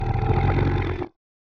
Boss Hit.wav